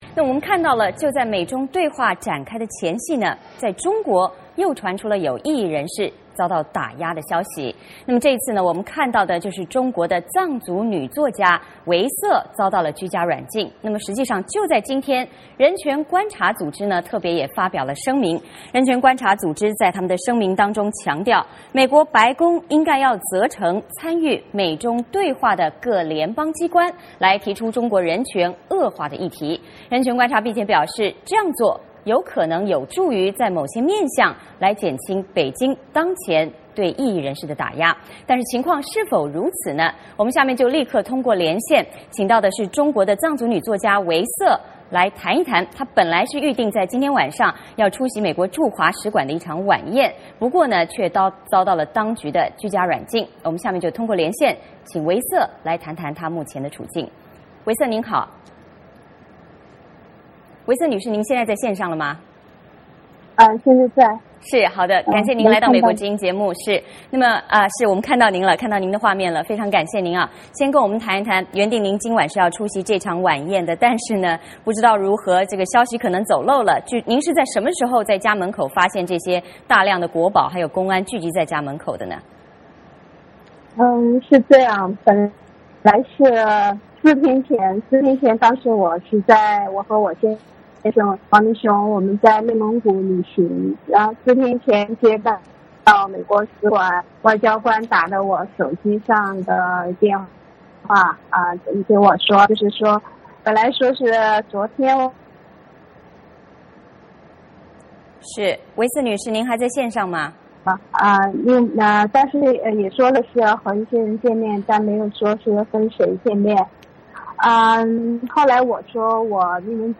VOA连线：藏族女作家唯色谈遭软禁缘由和目前处境
藏族女作家唯色今晚本来预计要出席美国驻中国大使馆的晚宴，结果却遭到了当局的软禁。我们连线唯色，请她谈谈她目前的处境。